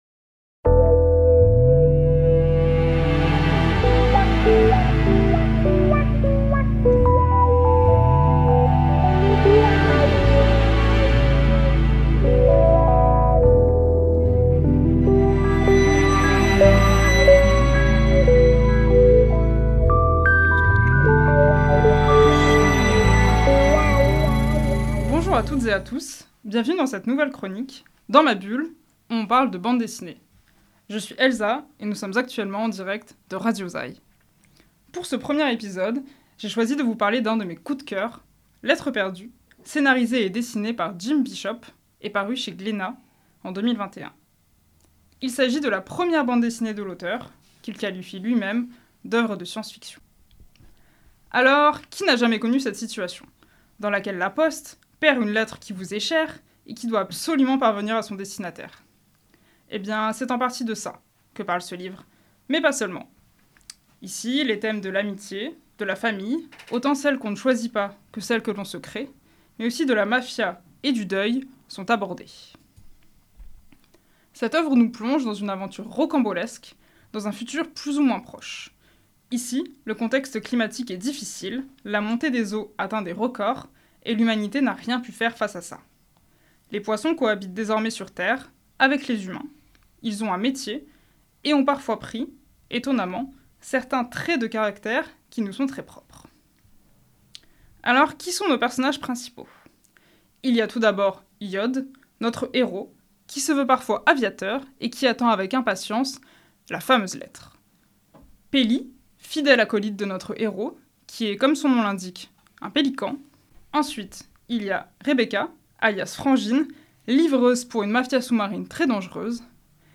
Cette chronique (qui se veut) hebdomadaire vous parle, en quelques minutes, d’une œuvre ou d’une série de livres.